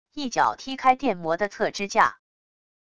一脚踢开电摩的侧支架wav音频